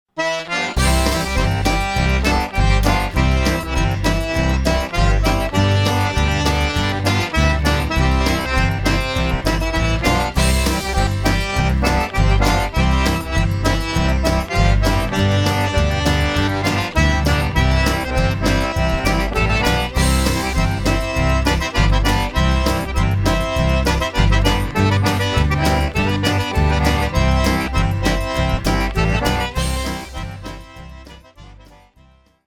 Intro Cajun Accordion Lessons